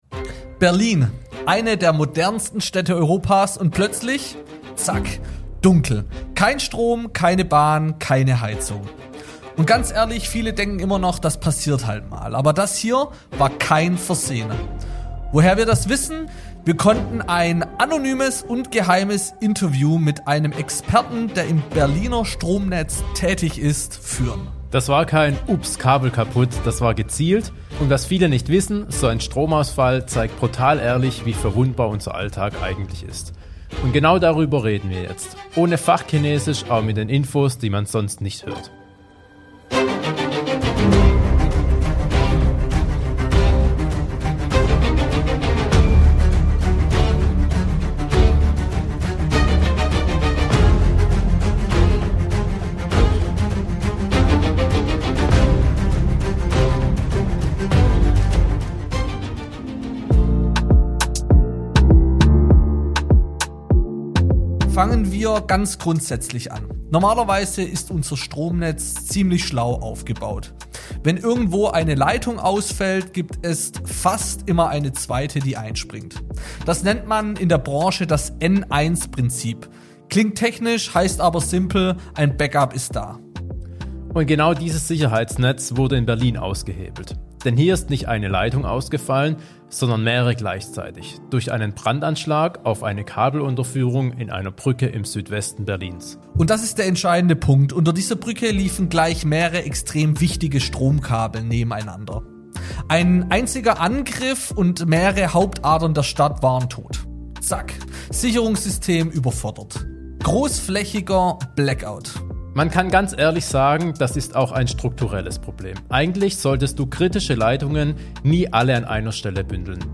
Geheimes Interview